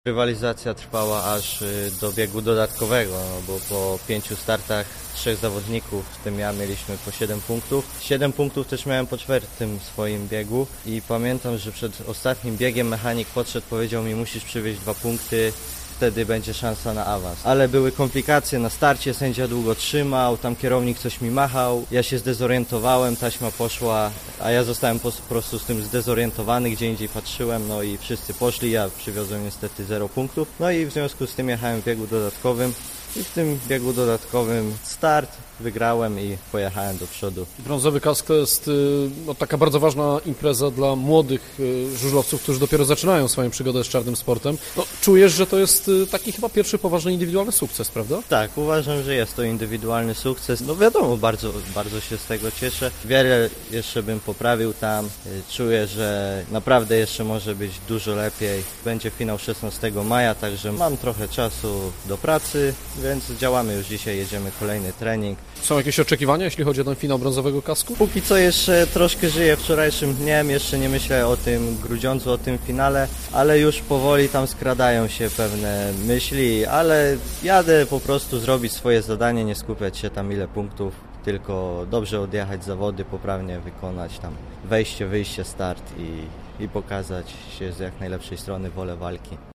Posłuchaj rozmowy z juniorem Falubazu: